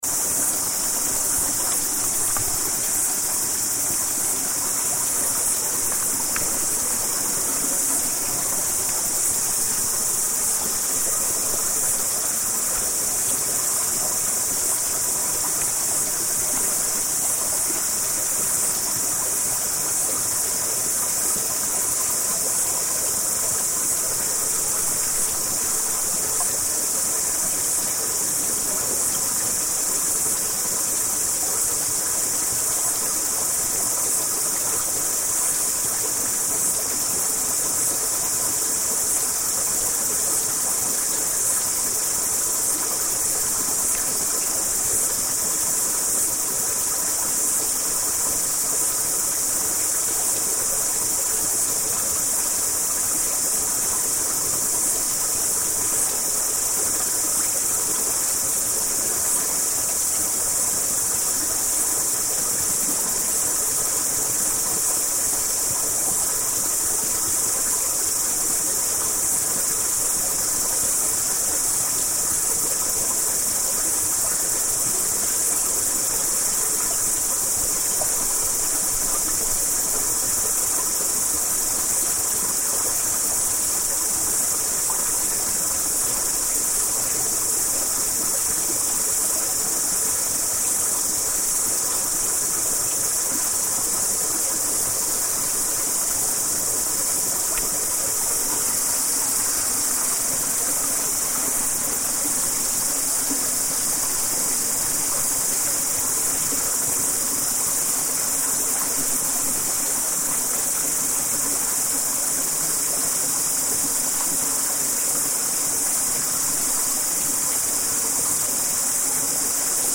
Recorded at the Morere Hot Springs between Gisborne and Napier. You can hear cicadas and a stream running past the Hot Springs.